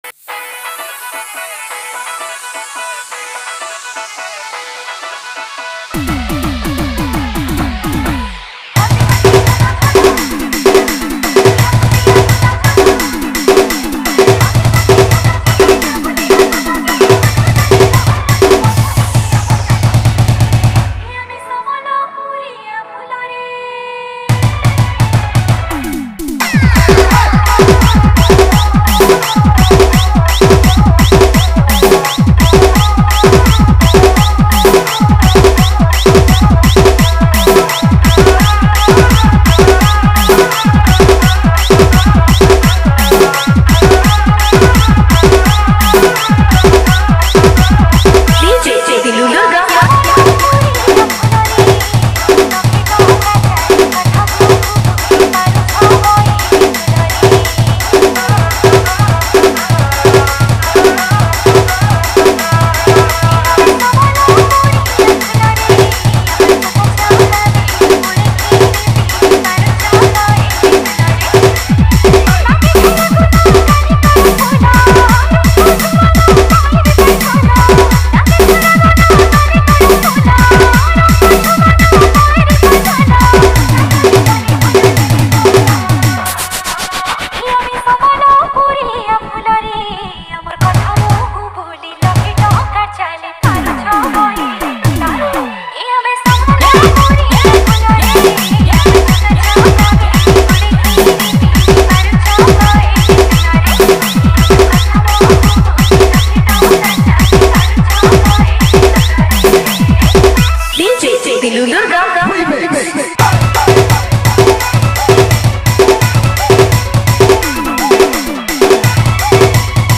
Category:  New Sambalpuri Dj Song 2023